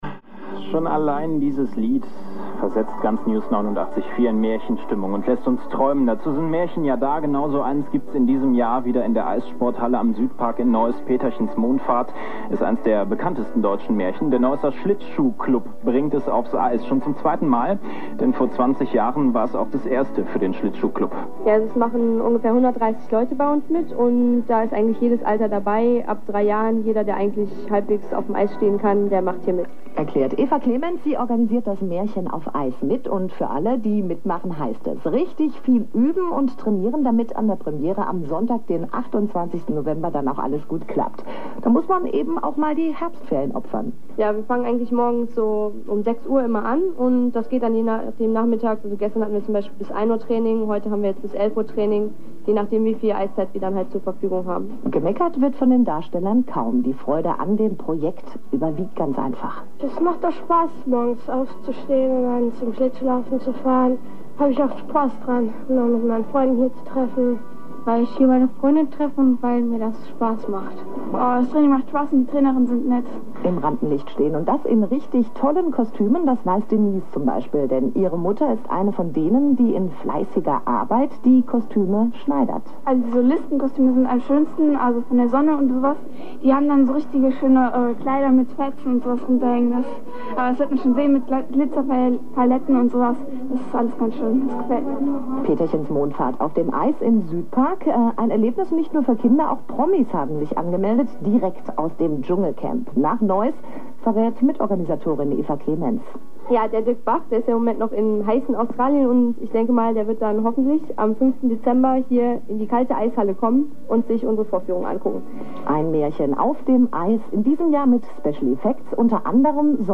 NEWS894-Interview.mp3